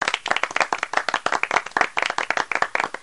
Applause for you
Applause-for-you.mp3